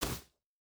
Land Step Grass B.wav